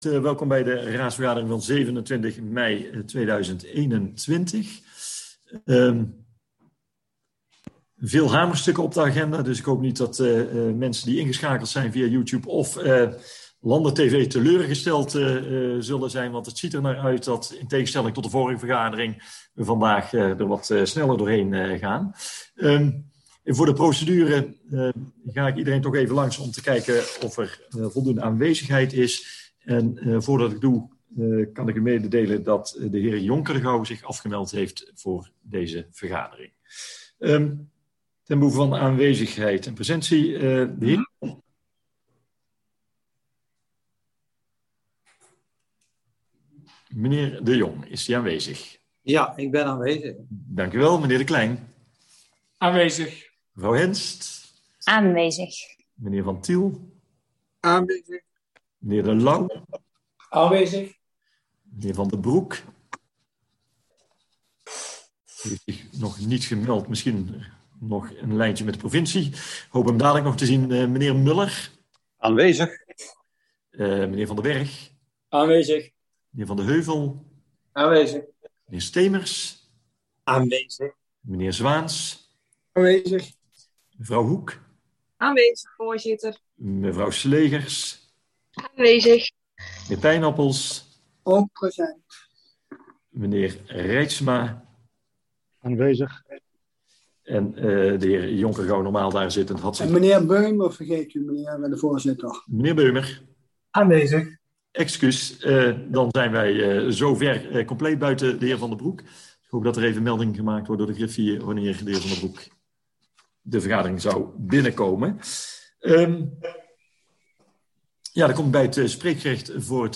Agenda Landerd - Raadsvergadering donderdag 27 mei 2021 19:30 - 19:35 - iBabs Publieksportaal
Locatie Zoom Voorzitter M.C. Bakermans